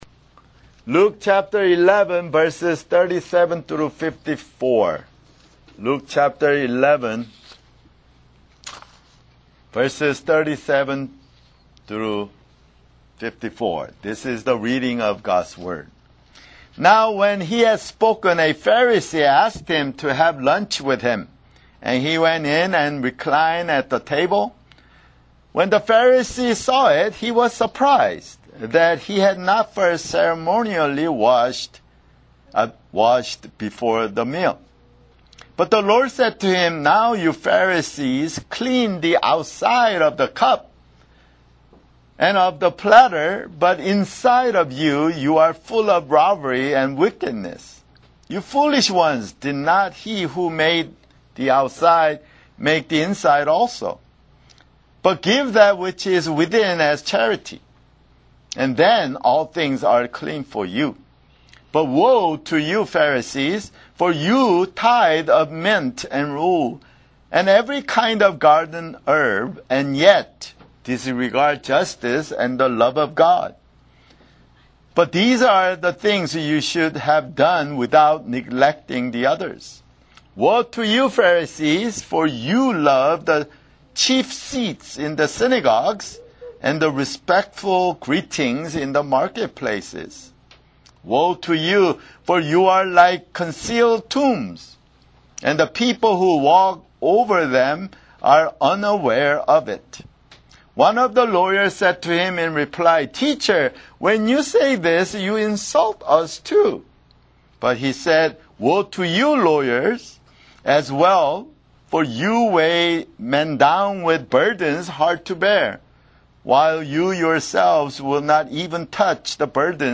[Sermon] Luke (84)